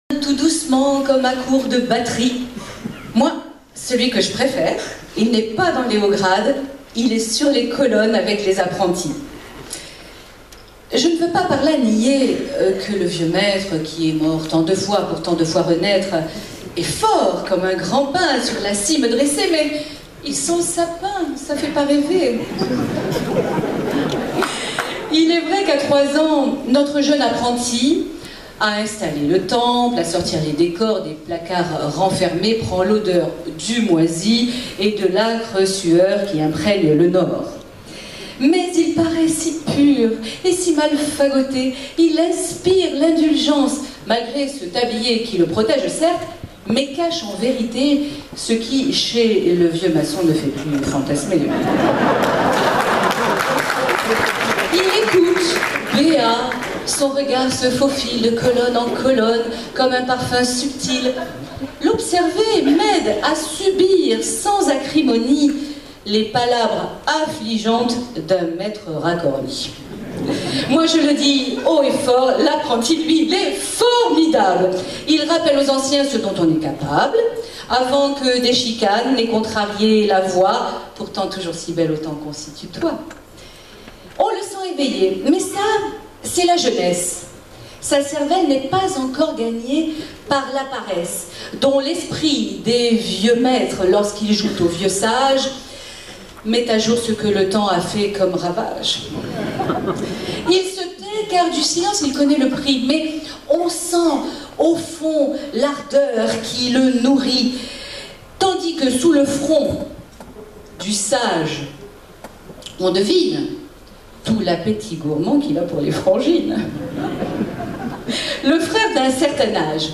Monologue dit de préférence par une femme
Extrait, enregistrement public